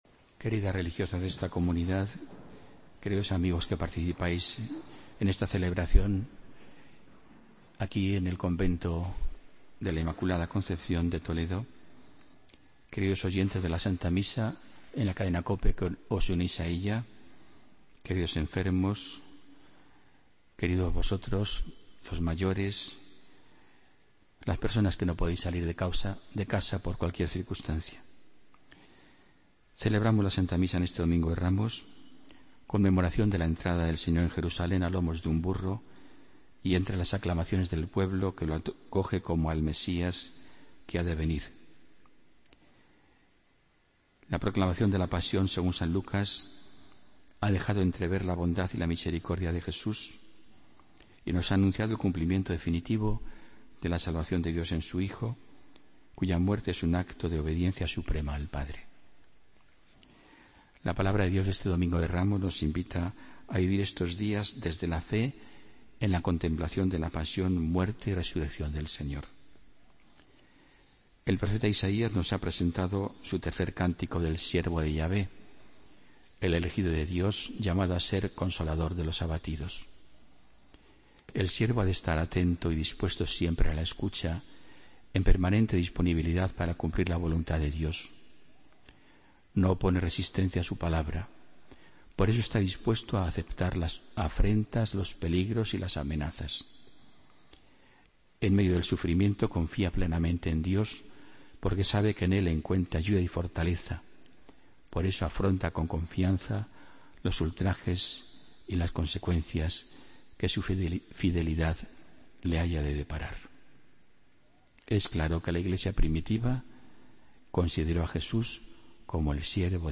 Homilía del domingo 20 de marzo de 2016